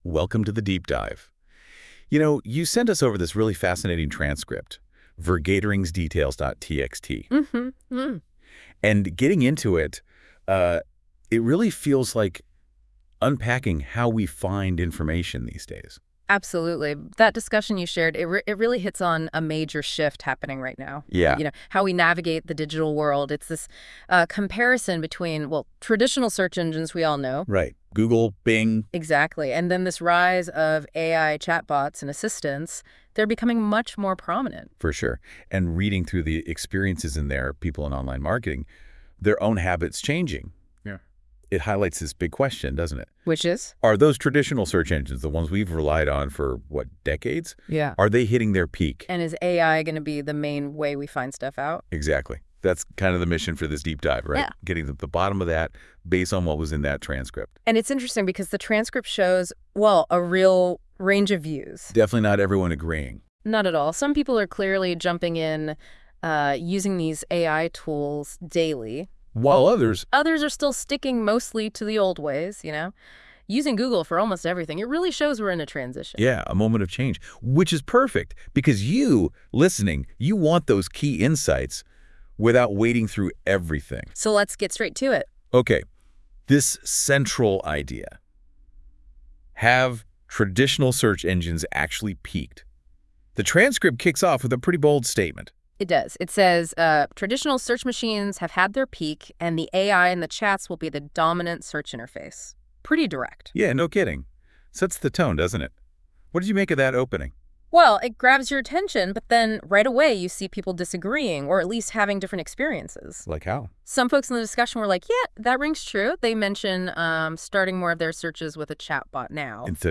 Er werden verschillende hulpmiddelen gebruikt om de vergadering op te nemen en met behulp van AI-hulpmiddelen werd er vervolgens deze blogpost & korte podcast van gemaakt.